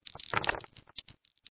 ShootDice.mp3